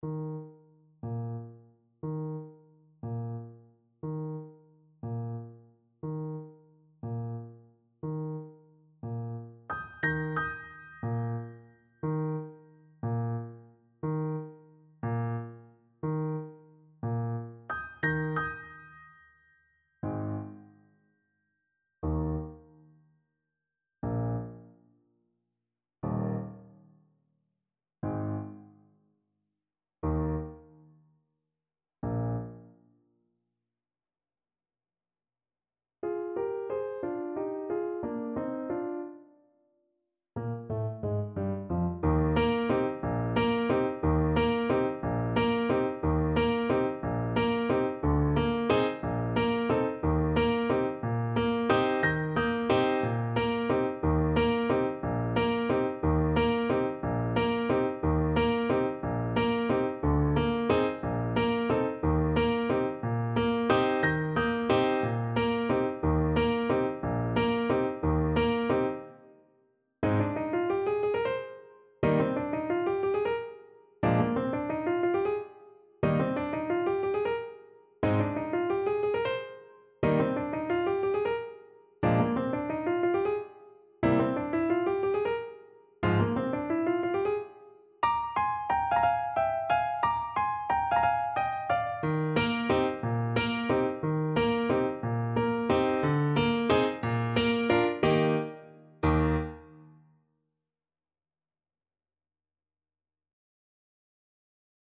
6/8 (View more 6/8 Music)
Pochissimo pi mosso = 144 . =60
Classical (View more Classical Trumpet Music)